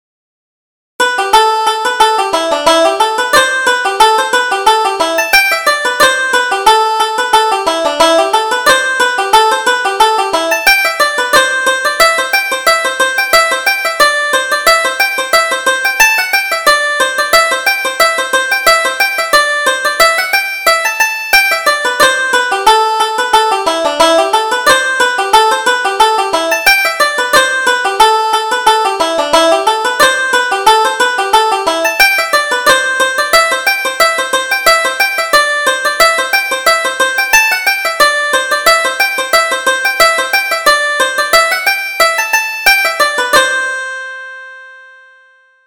Reel: The Cashmere Shawl